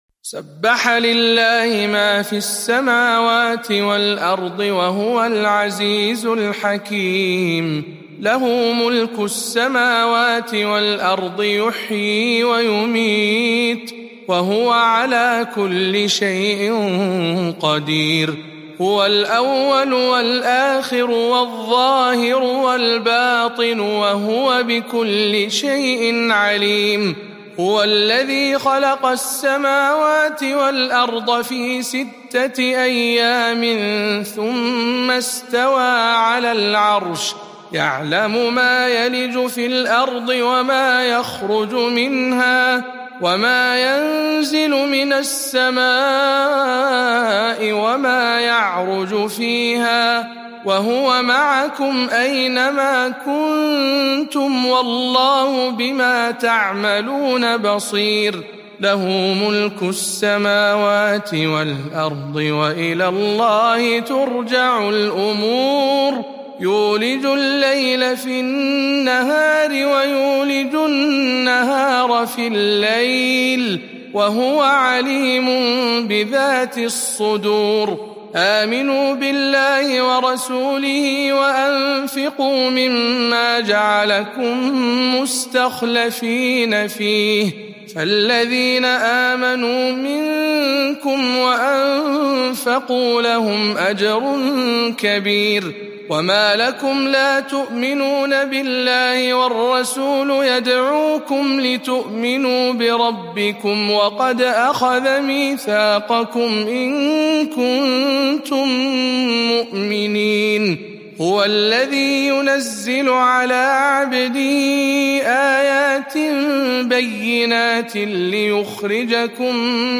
سورة الحديد برواية شعبة عن عاصم